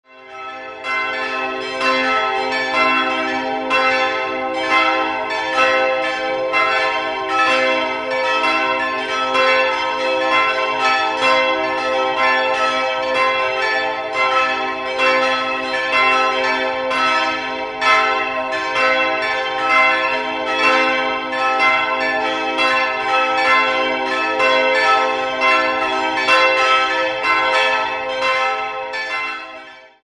4-stimmiges Salve-Regina-Geläute: c''-e''-g''-a''
Die vier Läuteglocken sind Teil eines insgesamt 12-stimmigen Glockenspiels mit der Tonfolge: c''-e''-f''-g''-a''-b''-h''-c'''-d'''-e'''-f'''-g'''. Die Wolfgangsglocke c'' wurde außerdem in Dur-Rippe gegossen.